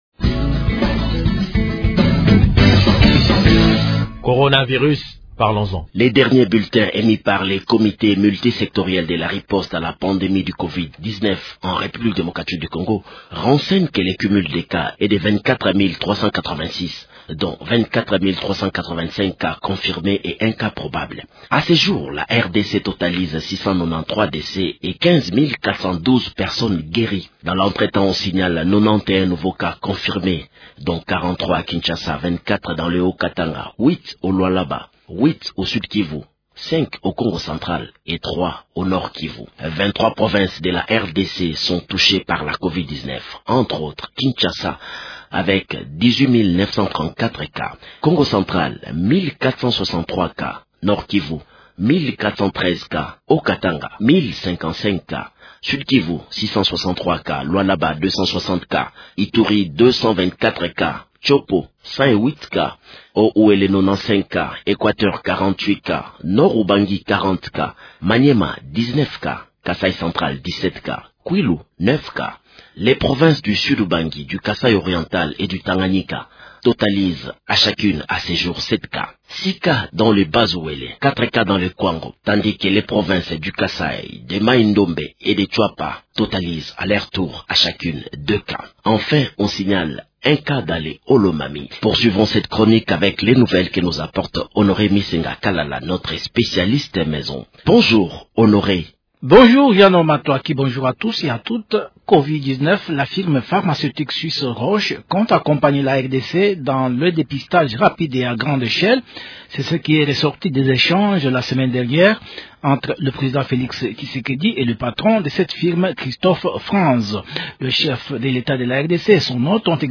Le partenaire du jour, c’est le gouverneur de Mai-Ndombe Paul Mputu Boleilanga qui sollicite l’appui du Comité national de riposte contre le coronavirus pour faire face à cette maladie qui a touché sa province depuis peu.